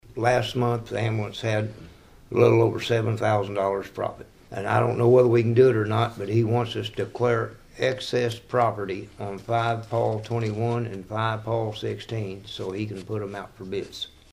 For what felt like the first time in ages, the White County Board gathered without a considerable crowd Tuesday night.
Board member Ken Usery spoke on his behalf.